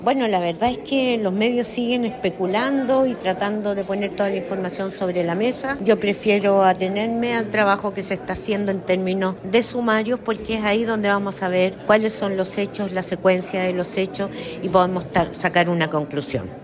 Mientras tanto, la diputada del Frente Amplio, Lorena Fries, apeló por esperar el sumario que realiza el Gobierno.